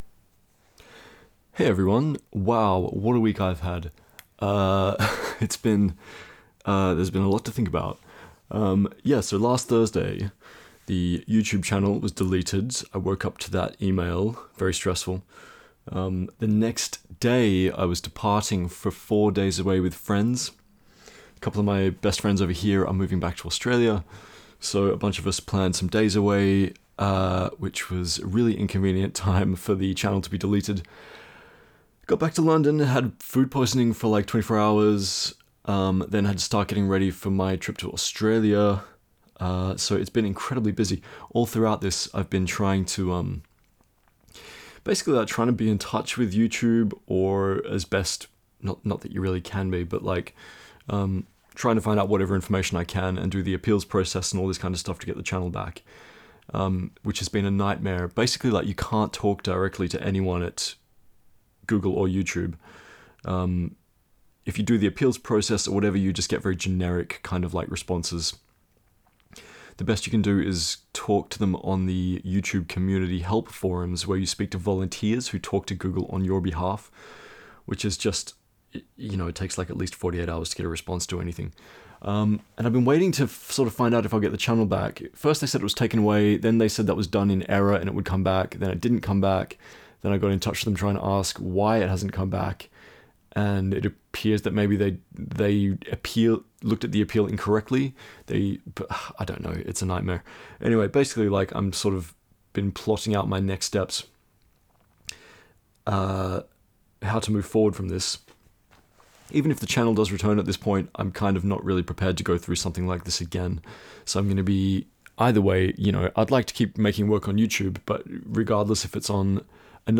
I really do appreciate it 🌷 BFx PS. Sorry if I sound stressed in the video.